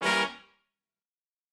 GS_HornStab-Edim.wav